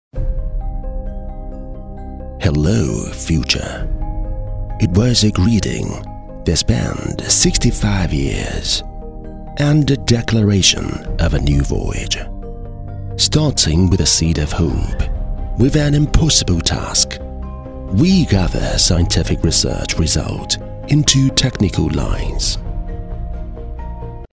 【专题】英式 专题2 中年音色 偏广告
【专题】英式 专题2 中年音色 偏广告.mp3